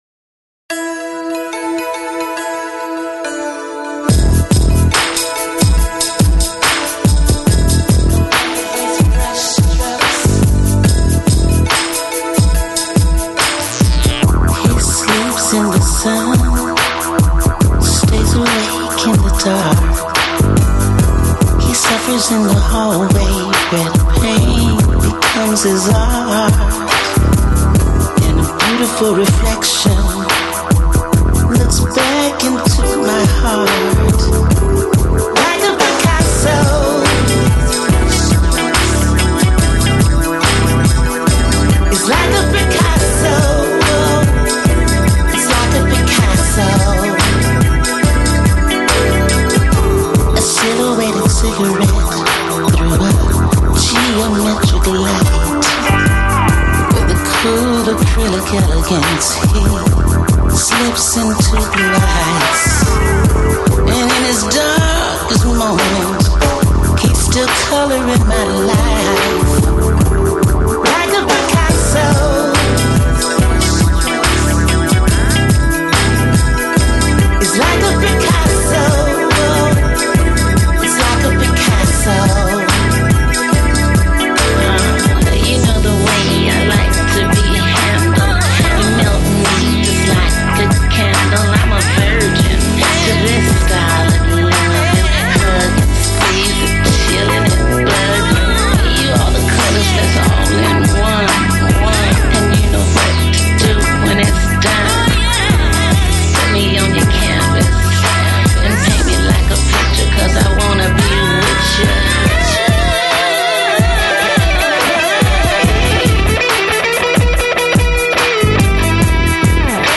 Жанр: Disco, R&B, Soul